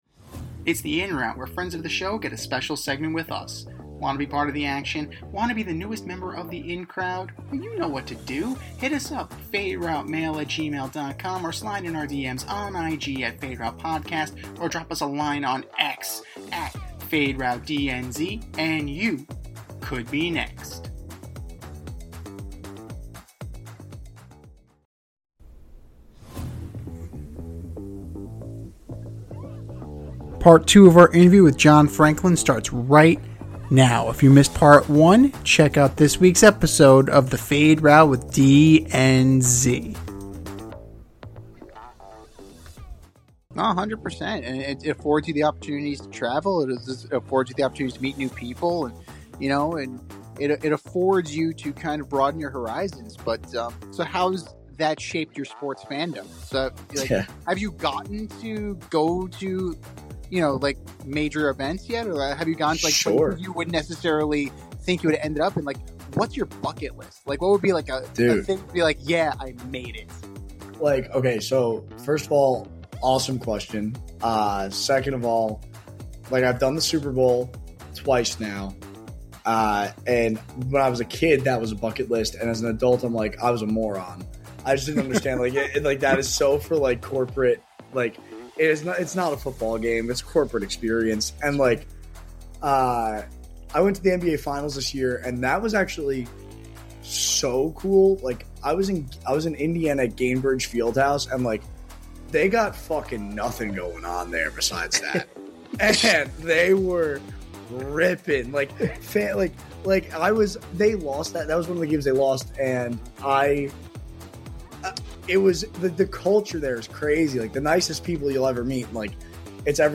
In part 2 of our interview